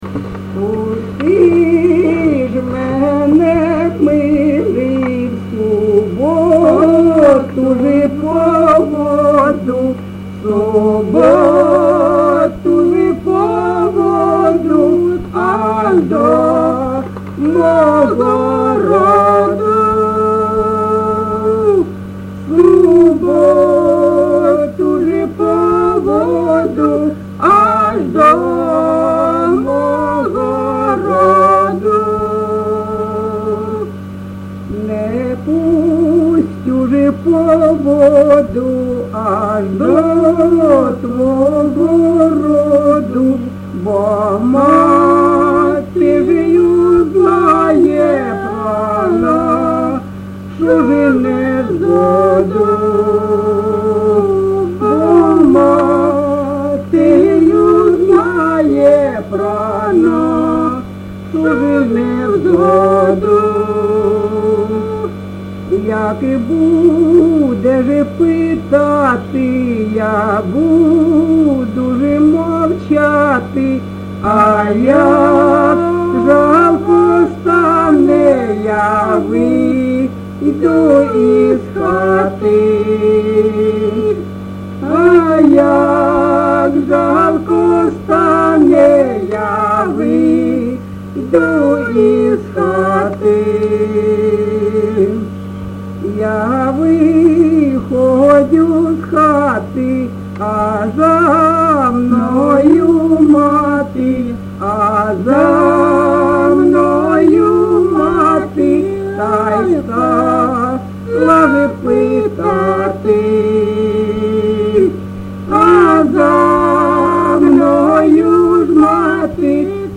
ЖанрПісні з особистого та родинного життя
Місце записус. Калинове Костянтинівський (Краматорський) район, Донецька обл., Україна, Слобожанщина